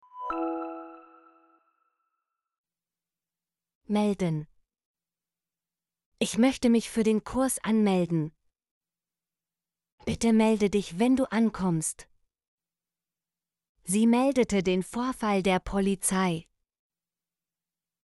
melden - Example Sentences & Pronunciation, German Frequency List